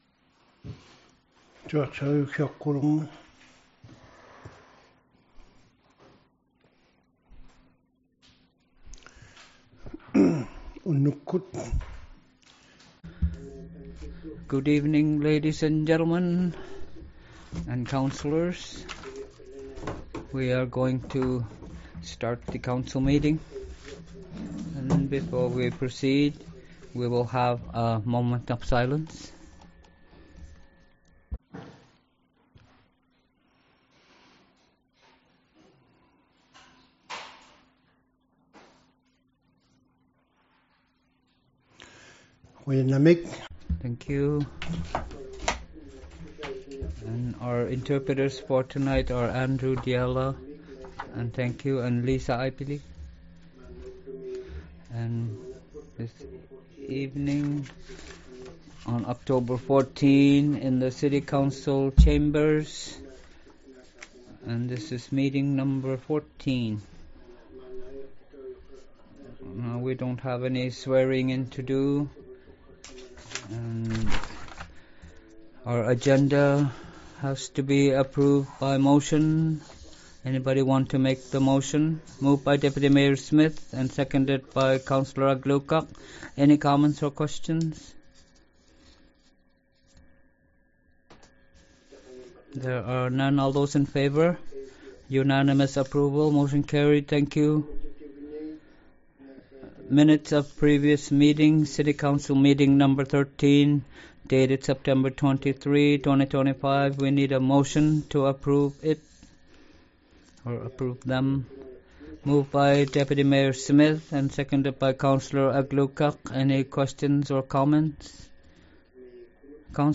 City Council Meeting #14 | City of Iqaluit